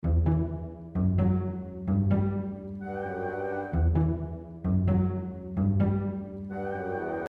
Short Orchestra